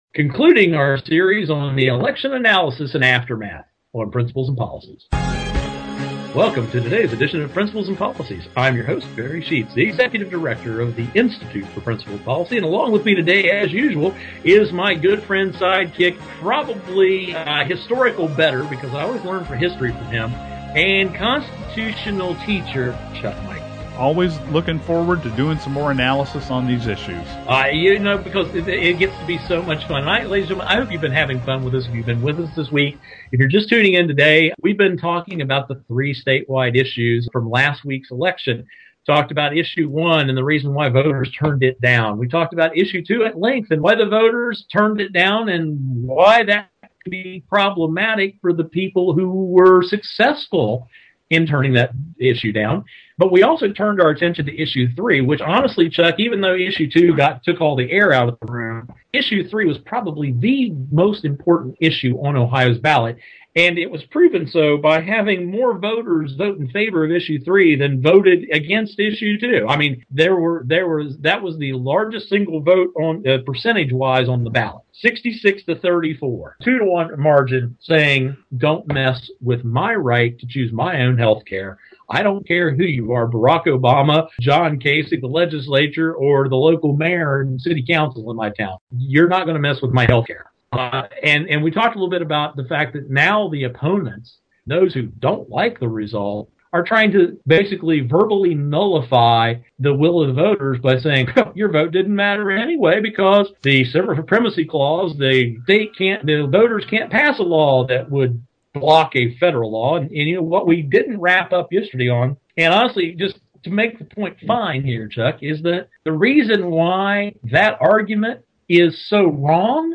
Our Principles and Policies radio show for Friday November 18, 2011.